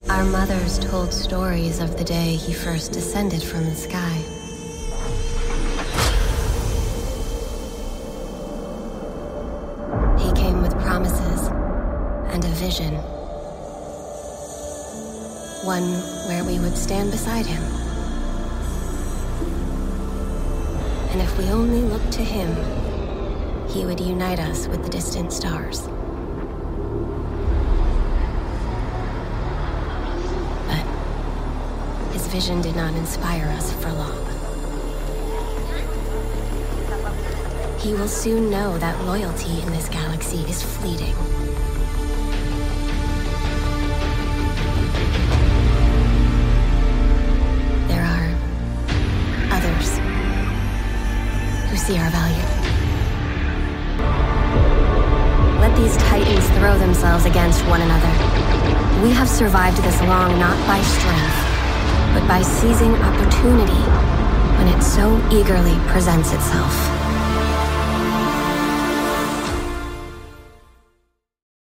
Teenager, Adult, Young Adult
standard us
gaming
comedic
quirky
reassuring
smooth